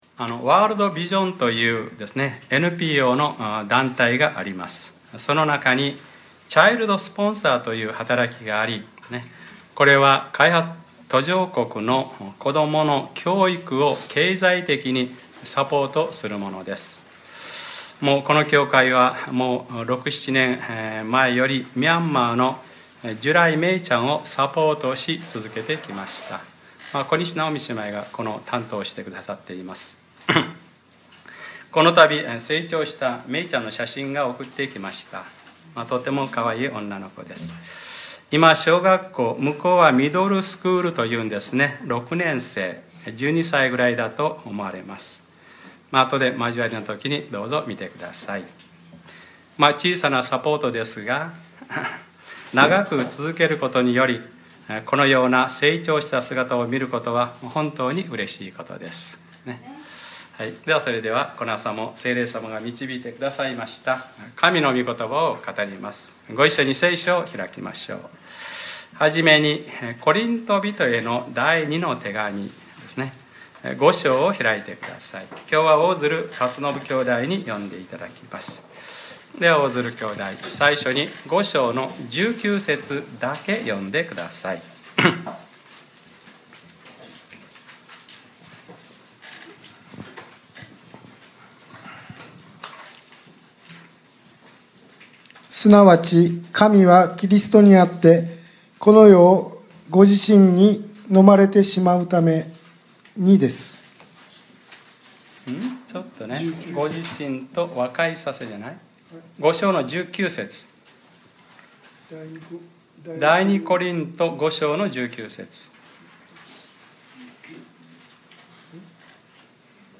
2015年07月12日（日）礼拝説教 『和解の努めを私たちに与えてくださいました』